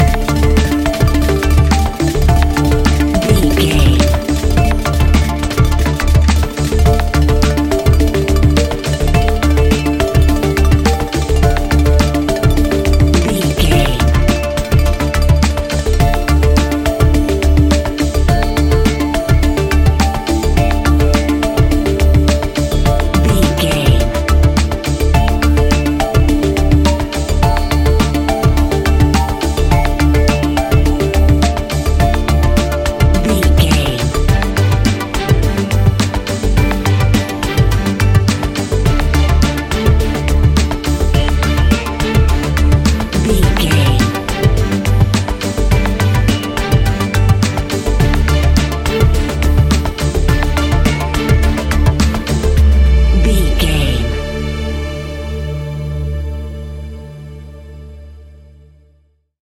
Ionian/Major
D
electronic
techno
trance
synths
synthwave
industrial
instrumentals